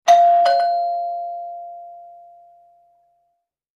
Door Bell 1; Typical Household Ding Dong Ring with Long Sustain In Release. Close Perspective.